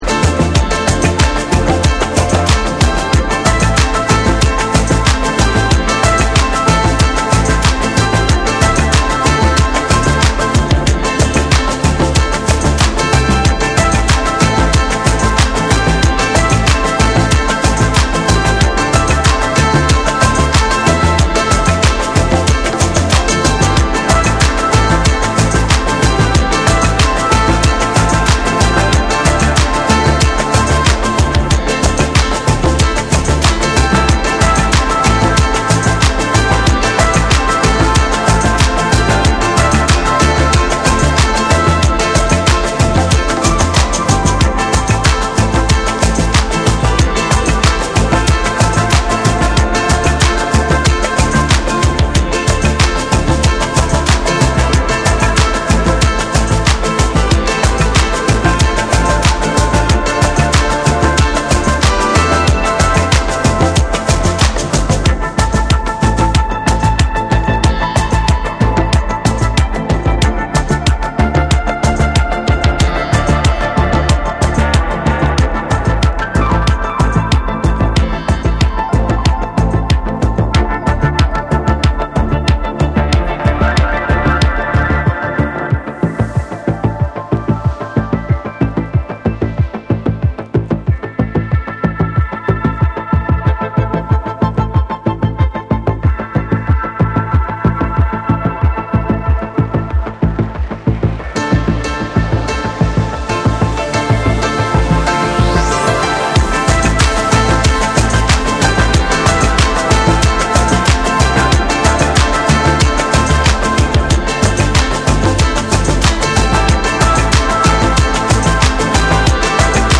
メロディアスでロマンティックな
DANCE CLASSICS / DISCO / RE-EDIT / MASH UP